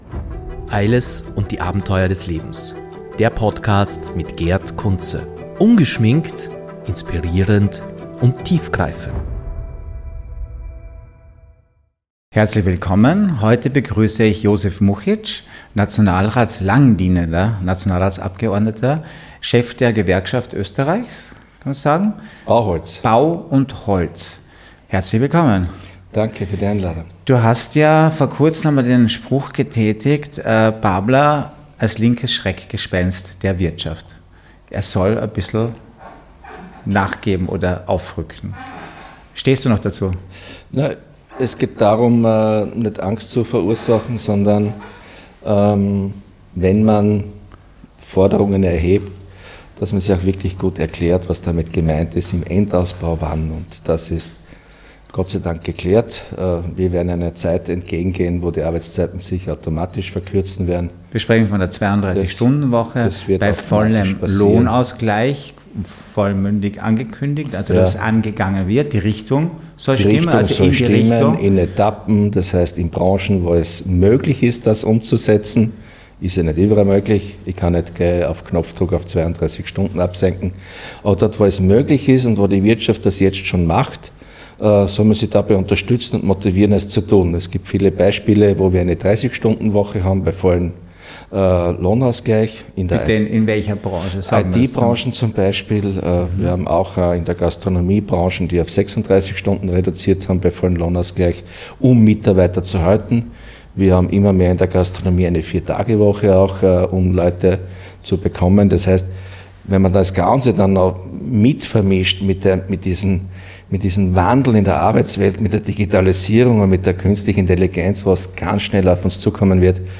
32-Stunden-Woche, Steuersystem Österreich & Babler - Politiktalk mit Josef Muchitsch (SPÖ)
Beschreibung vor 1 Jahr Diesmal ist der langjährige Abgeordnete zum Nationalrat Josef Muchitsch (SPÖ) zu Gast. In unserem Gespräch erörtern wir wichtige Fragen zur Arbeitswelt, einschließlich der potenziellen Einführung der 32-Stunden-Woche bei vollem Lohnausgleich.